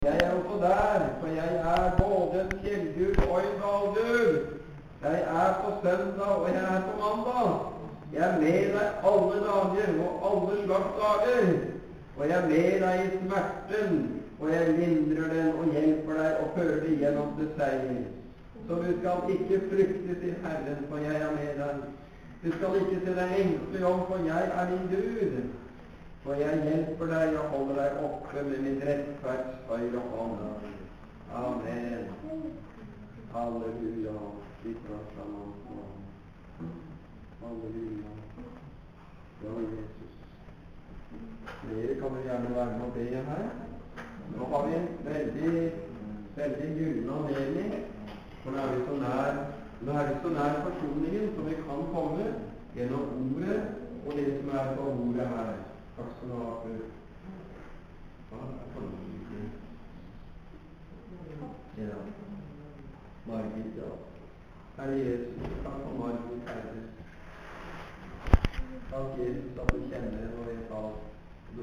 Betel Volda søndag 2.11.08.